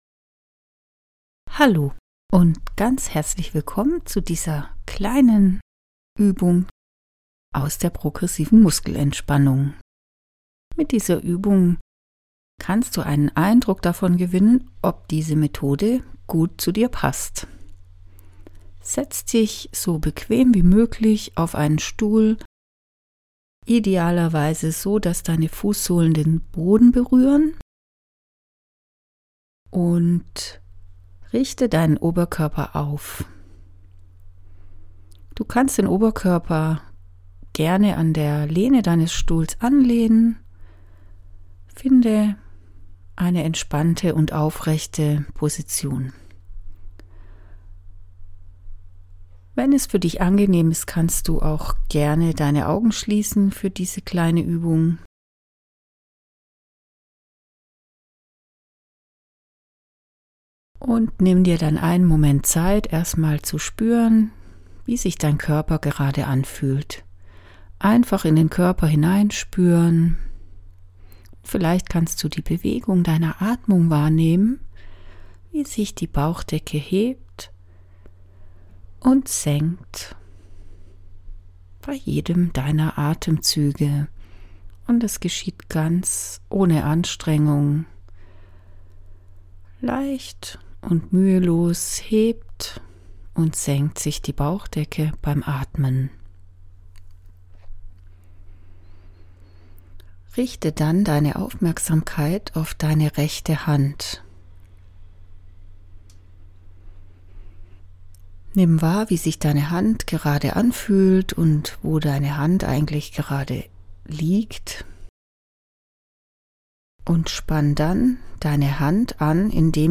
Übung 1: Progressive Muskelentspannung
Mit diesem kleinen Ausschnitt einer Übung kannst Du einen ersten Eindruck gewinnen, ob diese Methode gut zu Dir passt.